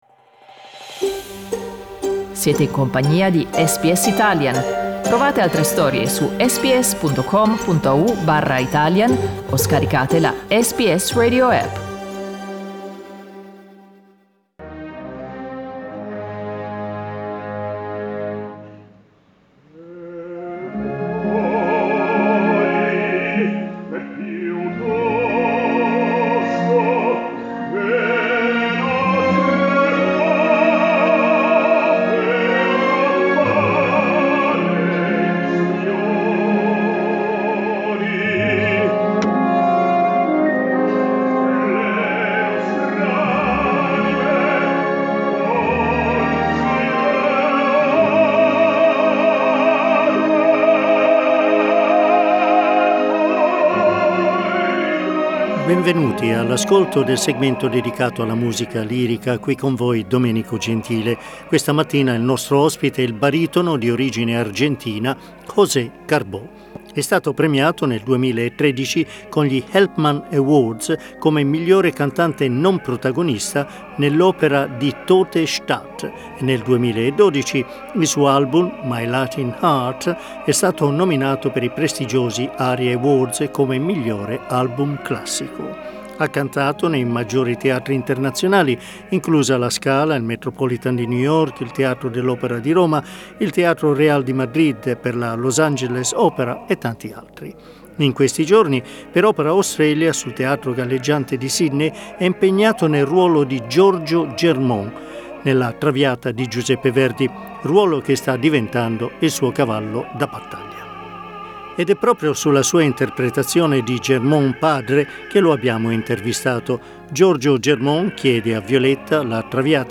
Ascolta l'intervista con José Carbó: LISTEN TO José Carbó, il perfetto Giorgio Germont SBS Italian 18:21 Italian José Carbó nella parte di Rodrigo con il basso Ferruccio Furlanetto nel Don Carlo di G. Verdi all'Opera House di Sydney.